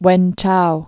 (wĕnchou, wŭnjō)